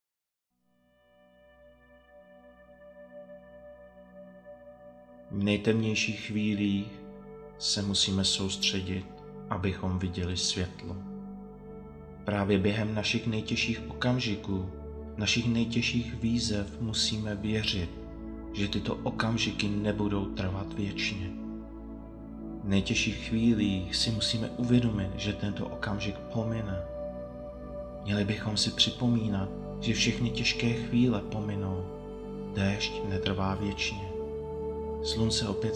AudioKniha ke stažení, 1 x mp3, délka 6 min., velikost 5,4 MB, česky